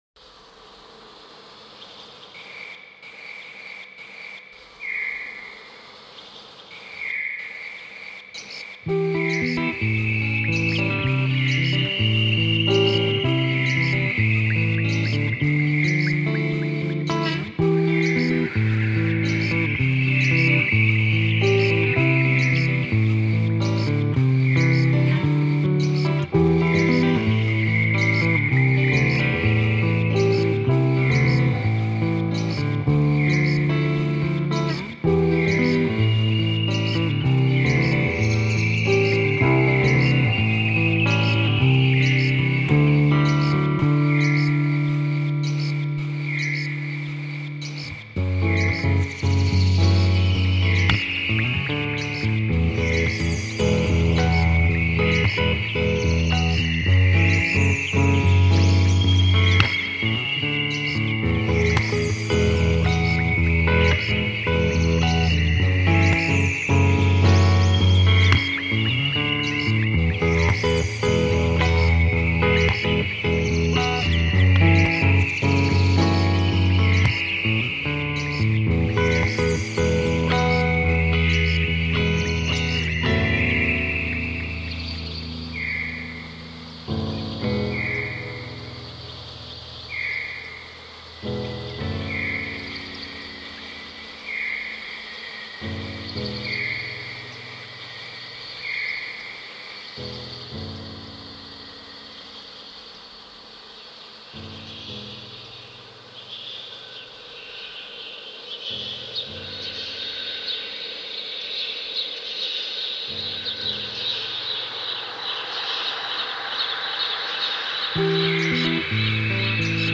Florence lockdown sound reimagined